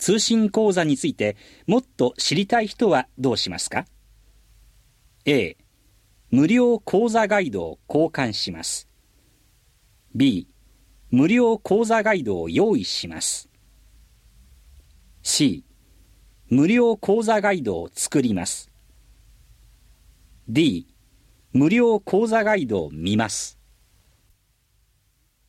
Conversation 03